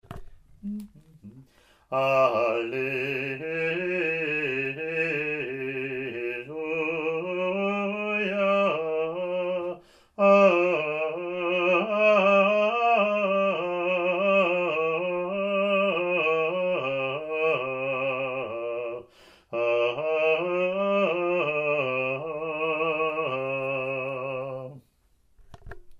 Alleluia Acclamation (Year A)
ot13a-alleluia-gm.mp3